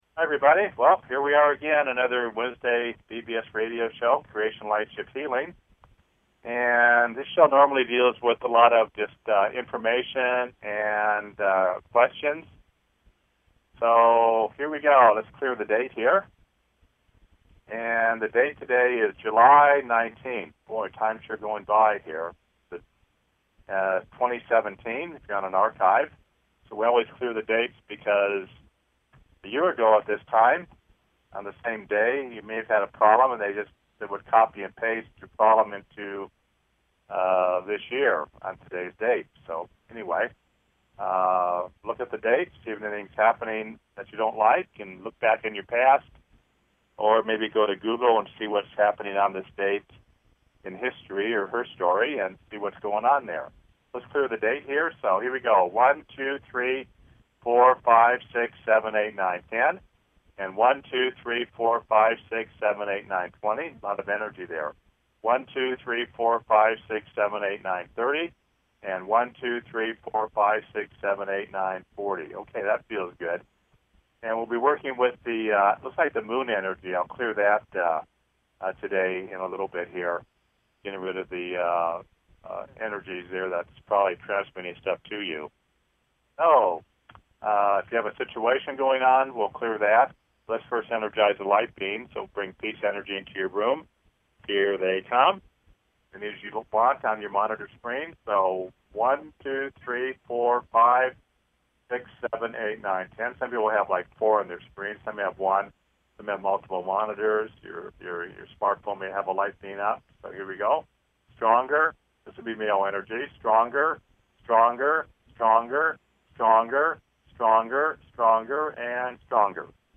Talk Show Creation Lightship Healings